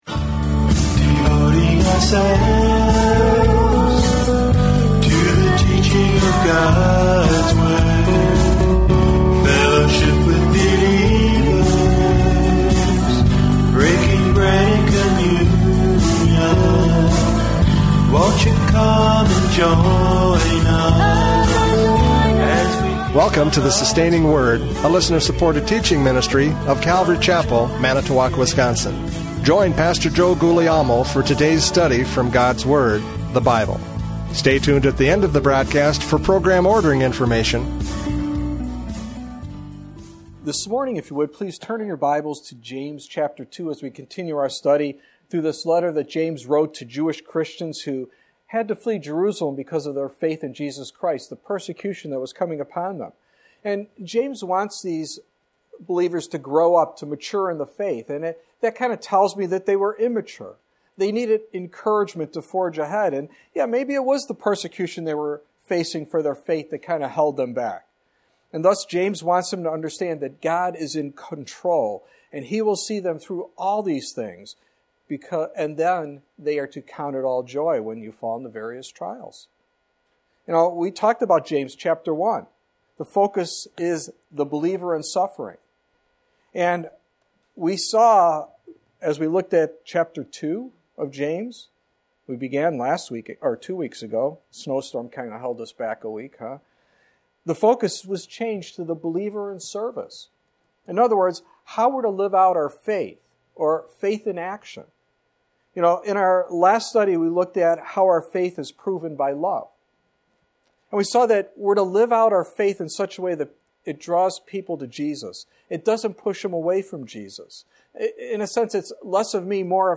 James 2:14-20 Service Type: Radio Programs « James 2:1-13 Faith is Proved by Love!